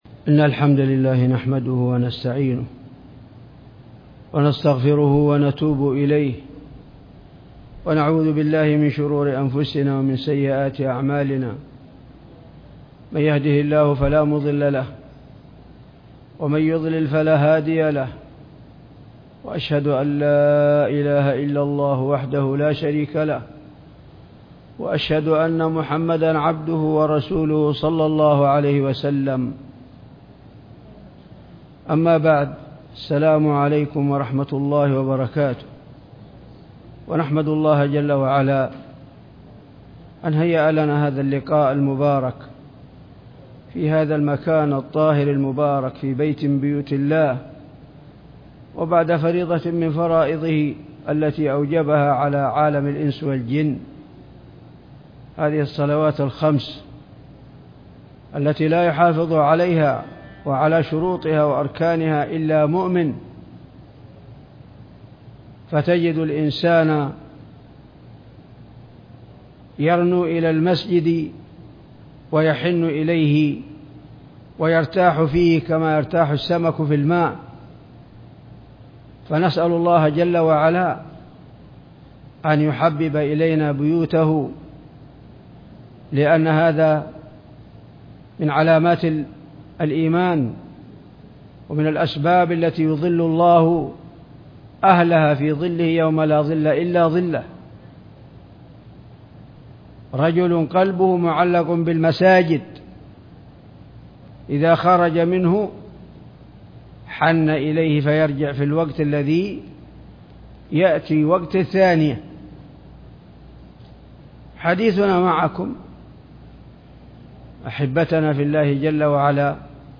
محاضرة
جامع قرية الحضرور بالطوال